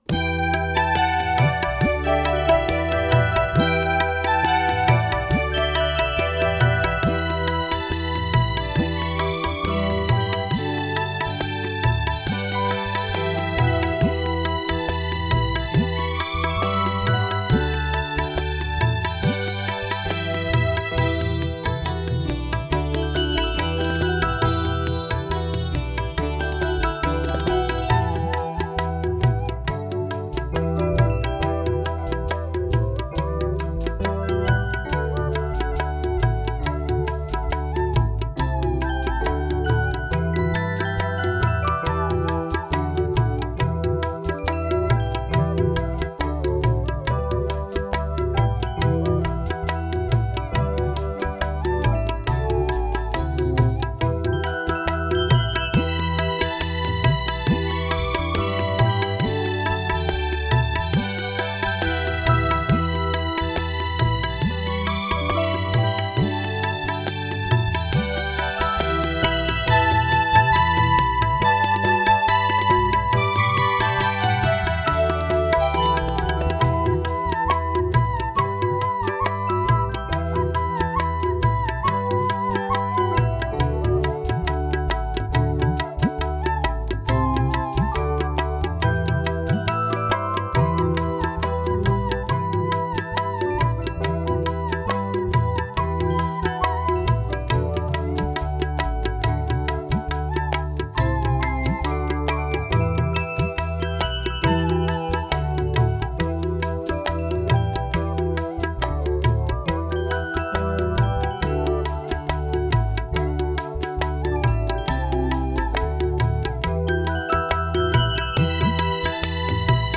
Minus One Tracks